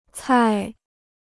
菜 (cài): dish (type of food); vegetable.